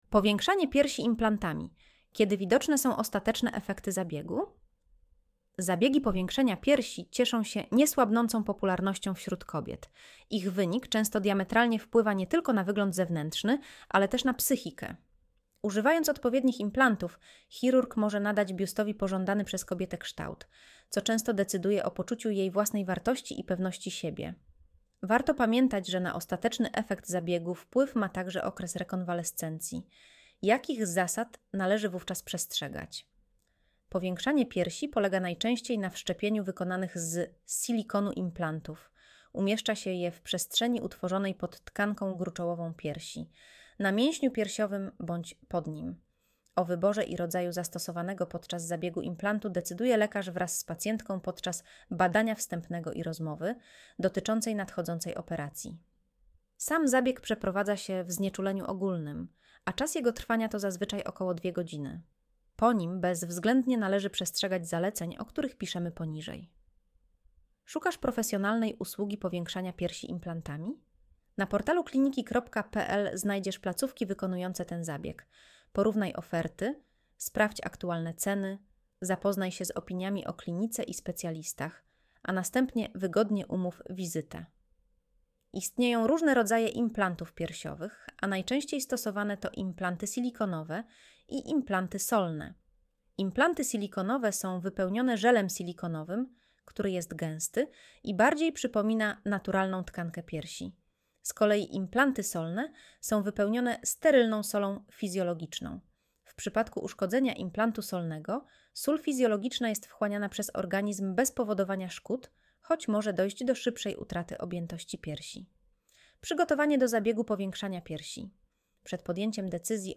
Streść artykuł Słuchaj artykułu Audio wygenerowane przez AI